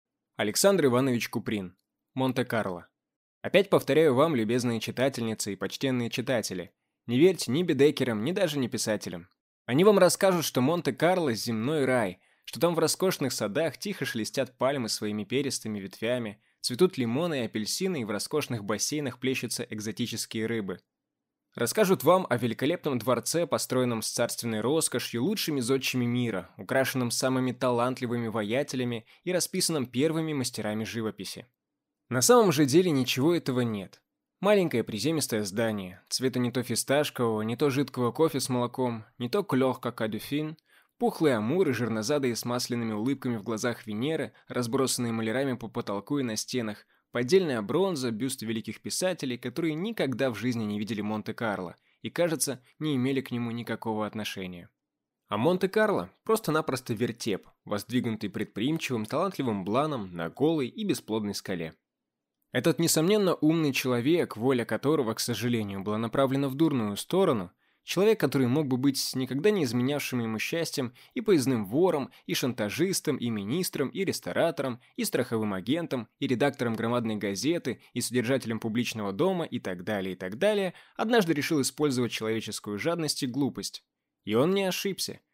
Аудиокнига Монте-Карло | Библиотека аудиокниг